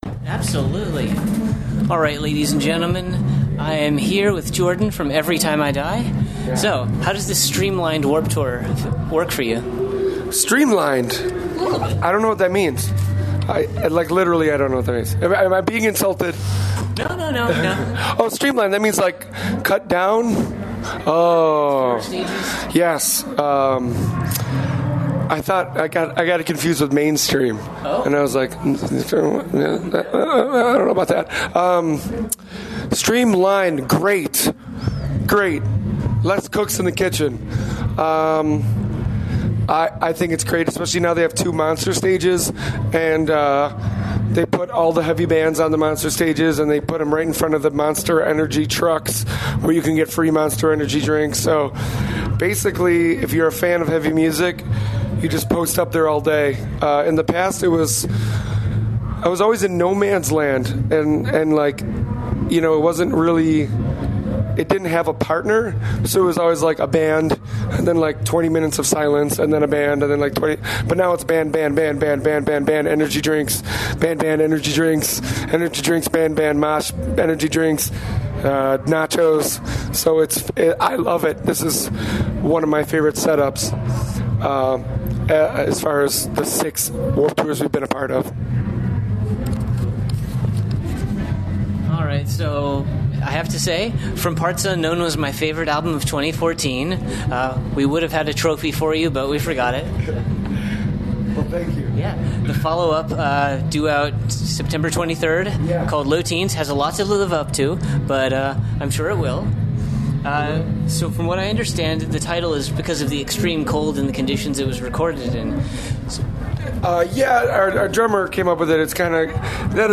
He was a bit breathless having just gotten off the stage performing a set and due to a communication error somewhere, instead of being at their tent for a signing, he had to bolt across the entire festival to make it to our humble press tent.